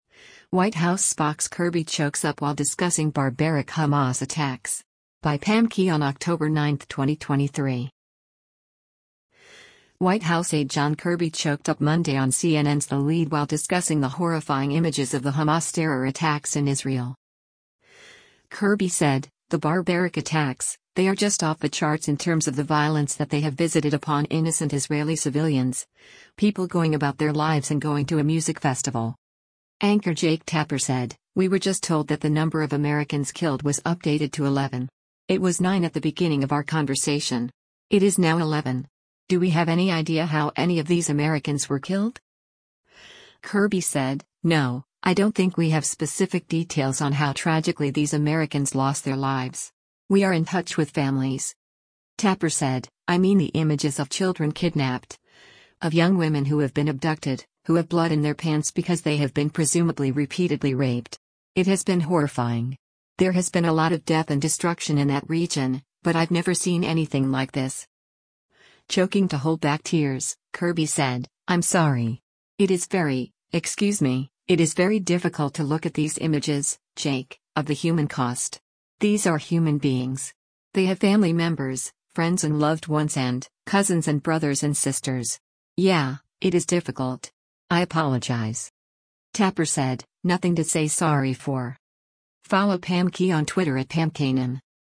White House Spox Kirby Chokes Up While Discussing 'Barbaric' Hamas Attacks
White House aide John Kirby choked up Monday on CNN’s “The Lead” while discussing the horrifying images of the Hamas terror attacks in Israel.